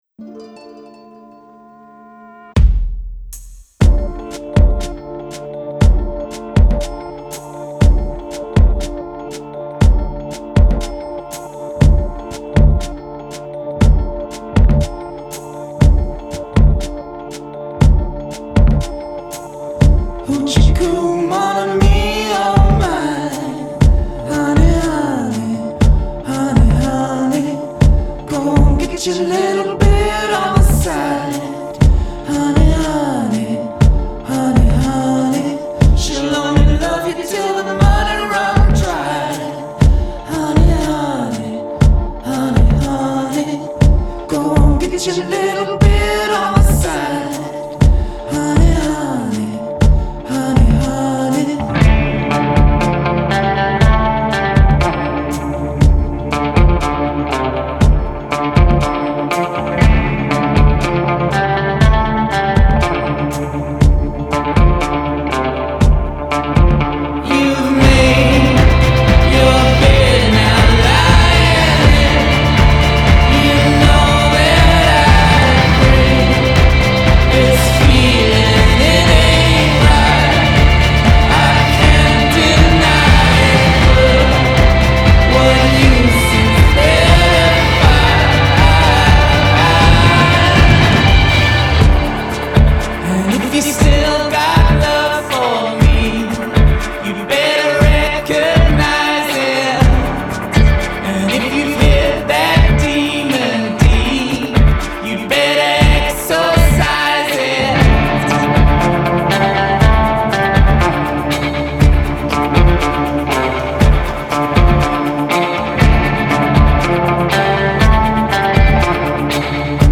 Жанр: Indie.